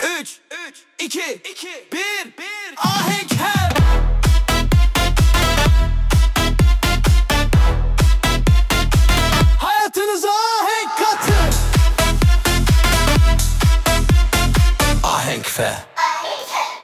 AI ile üretilen 58+ özgün müziği keşfedin
🎤 Vokalli 10.11.2025